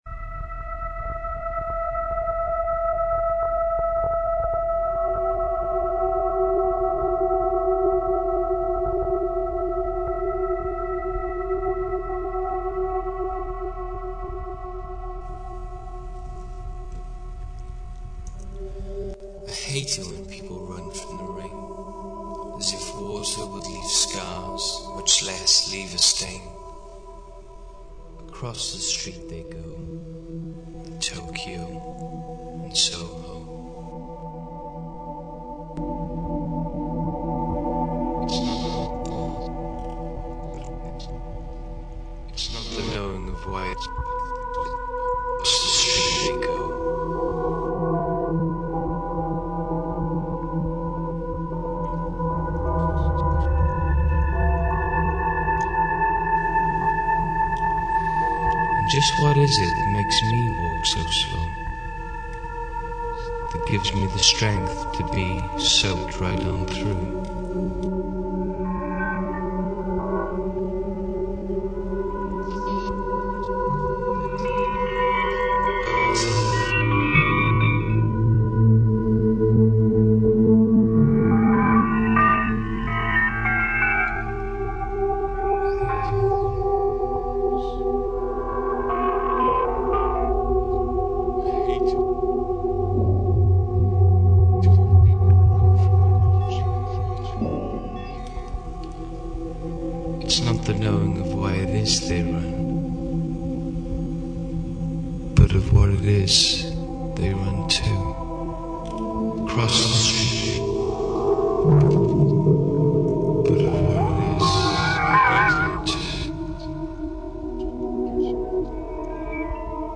Here it is as a musical piece.